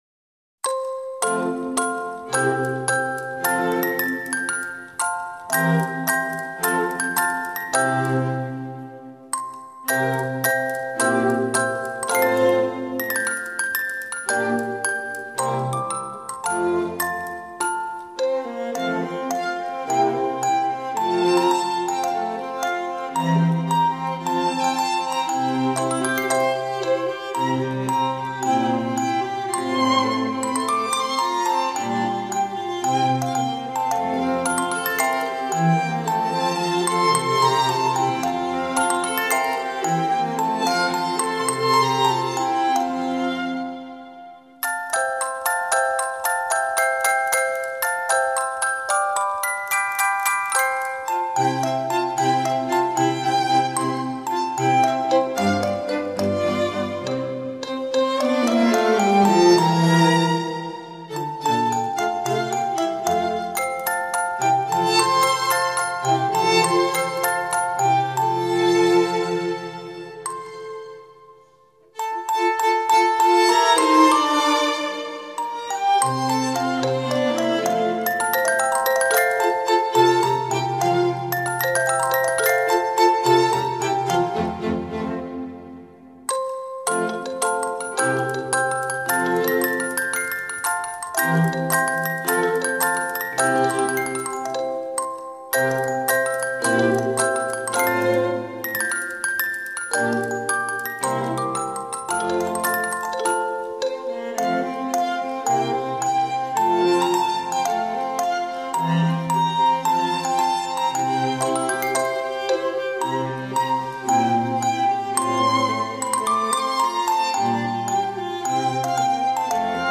Нежная классика Музыка